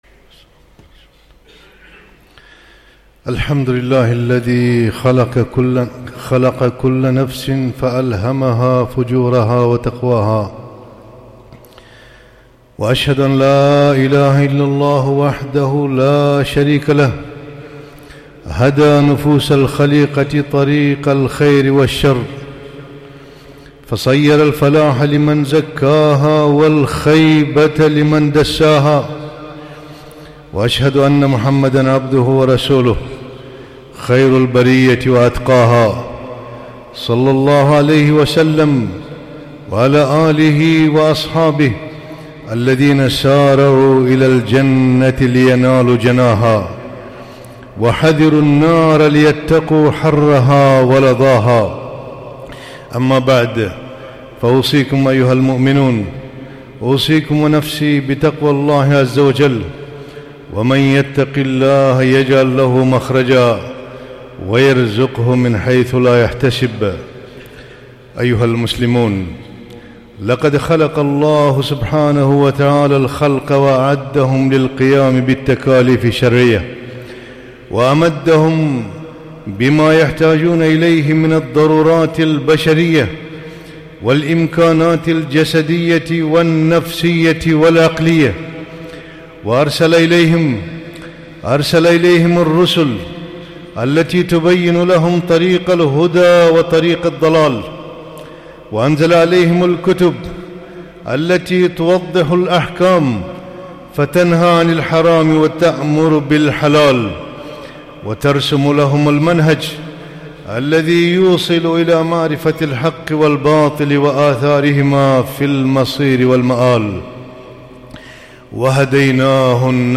خطبة - ( وهديناه النجدين)